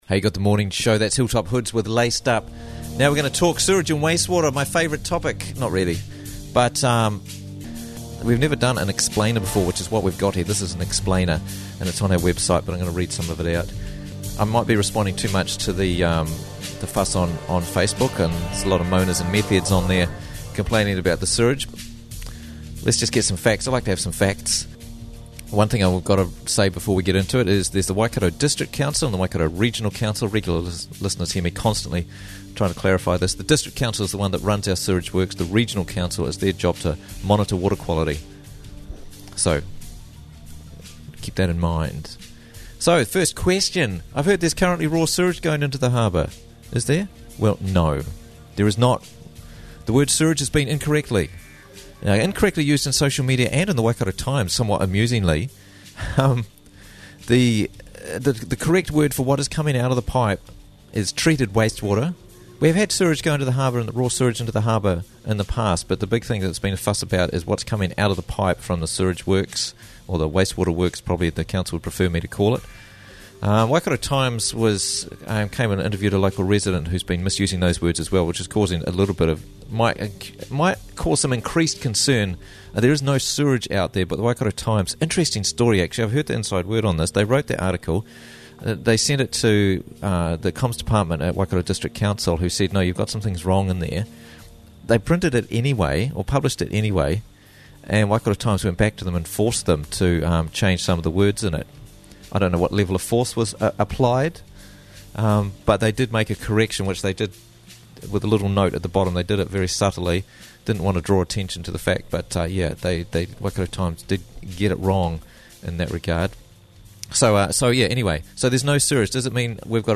interviewing himself about the big issue